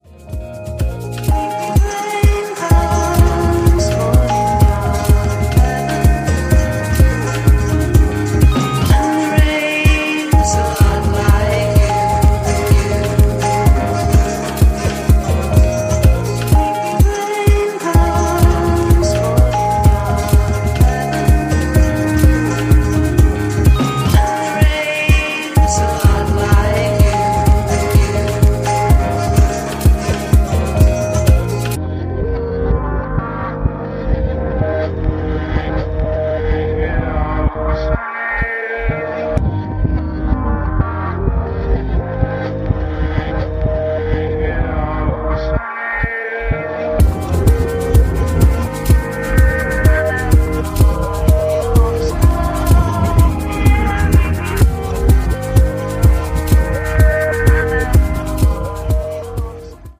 lo-fi sound